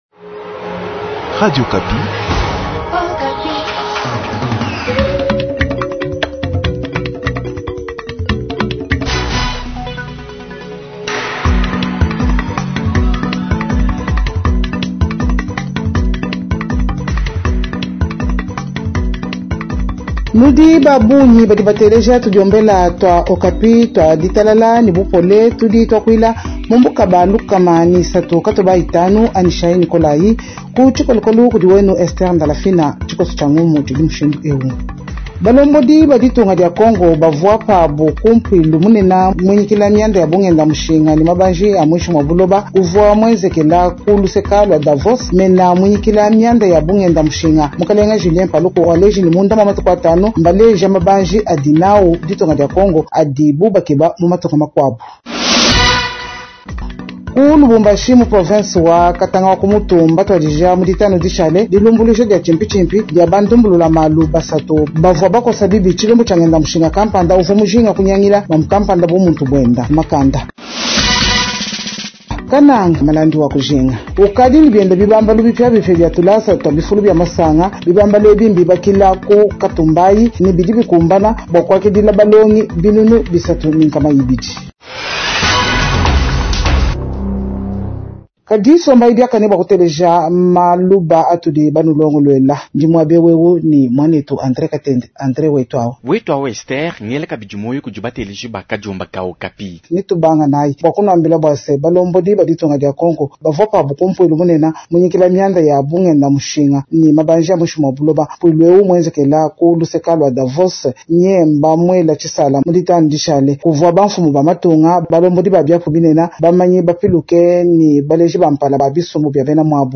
Journal de Tshiluba de lundi 26 janvier 2026